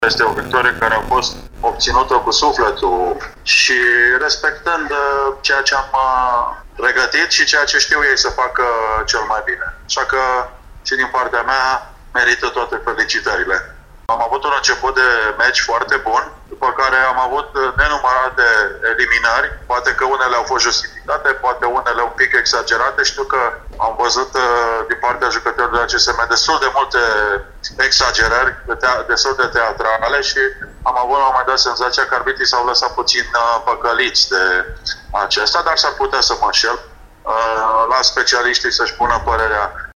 momentului declarațiilor